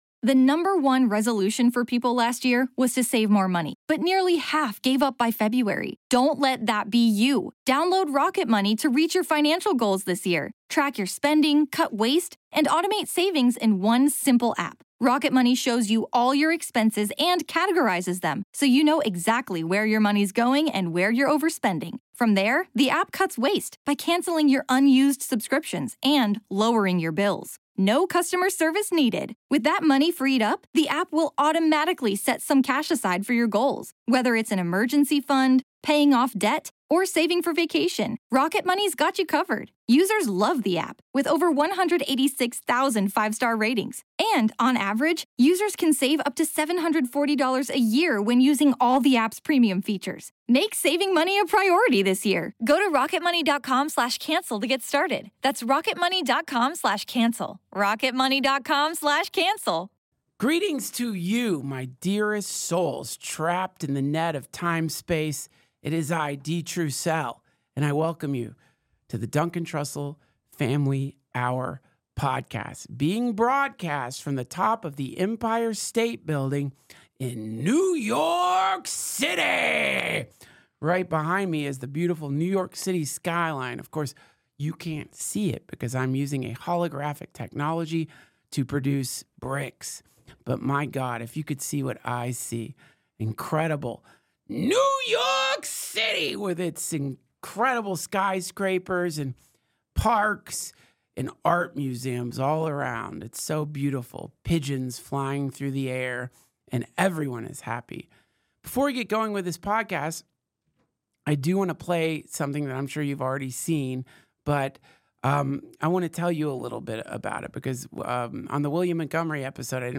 Prerecorded from the top of the Empire State building in a multimillion-dollar holographic projection booth, it's DTFH Live!